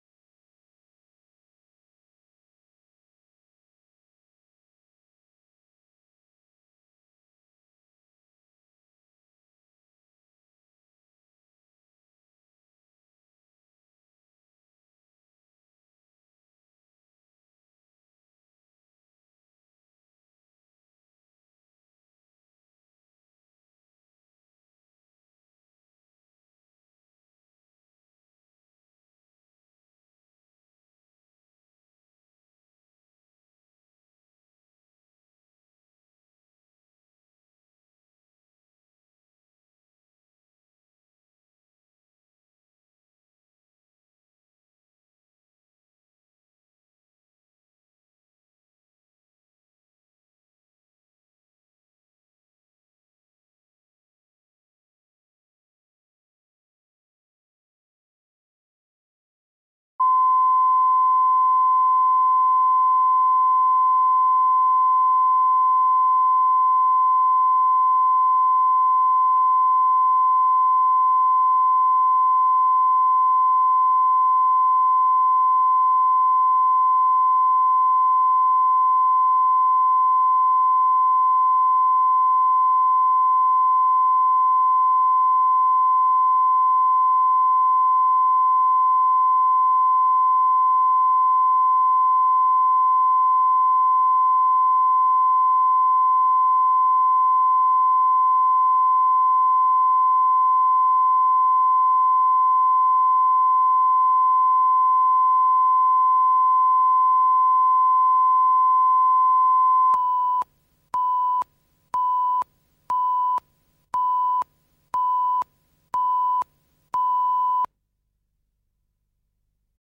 Аудиокнига Неидеальная невеста | Библиотека аудиокниг